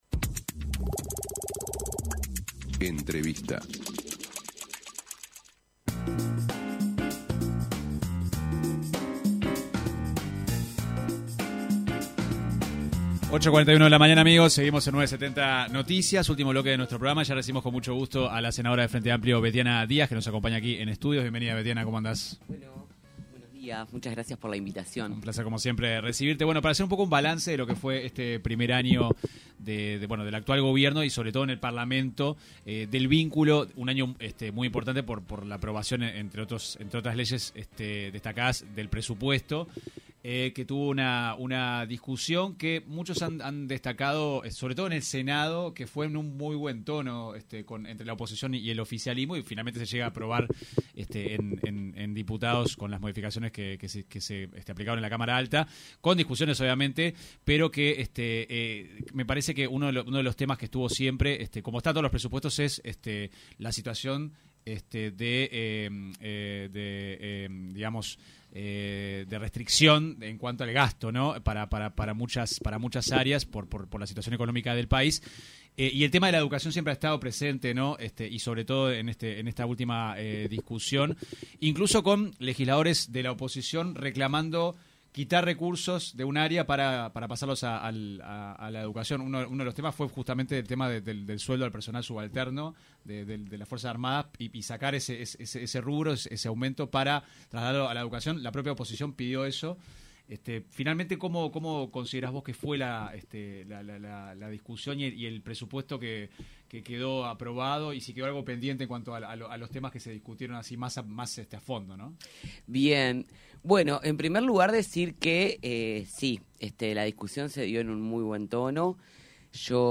La senadora del Frente Amplio, Bettiana Díaz se refirió en una entrevista con 970 Noticias al caso de la empresa Cardama, encargada de construir las patrulleras oceánicas para nuestro país. Criticó la gestión del senador nacionalista y exministro de Defensa, Javier García, así como al expresidente Luis Lacalle Pou y cuestionó el rol que jugó Armando Castaingdebat.